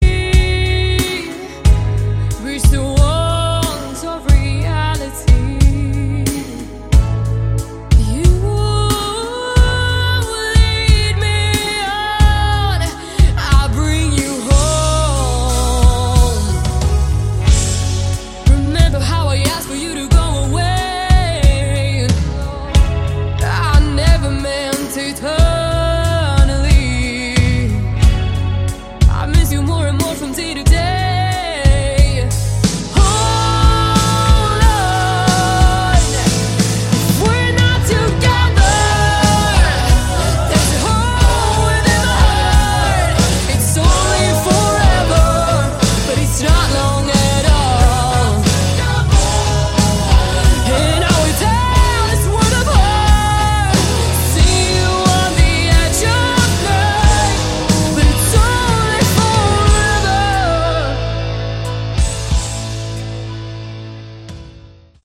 Category: Melodic Rock
keyboards, vocals
guitar, keyboards
bass
drums